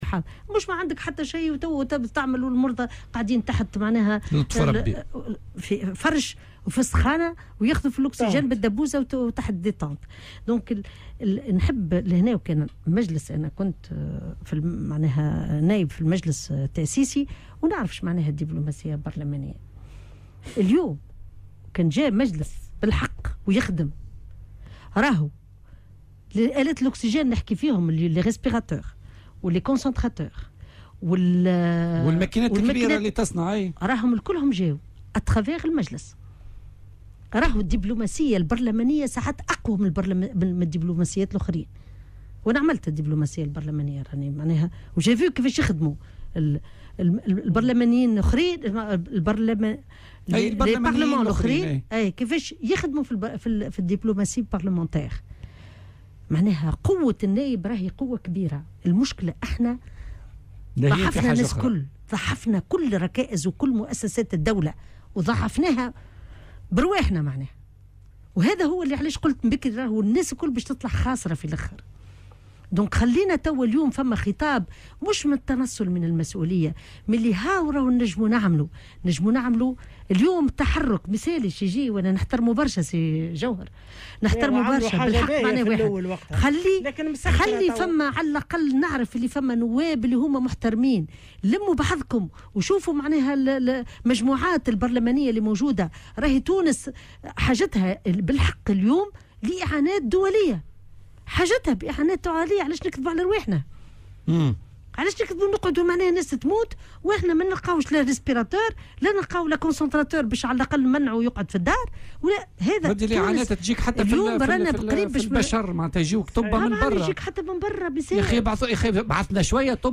وأضافت في مداخلة لها اليوم في برنامج "بوليتيكا" أنه لا يجب ترك المرضى يواجهون مصيرهم في ظل نقص الإمكانيات، داعية إلى ضرورة إحداث خلية أزمة "كوفيد" على مستوى رئاسة الحكومة والبرلمان.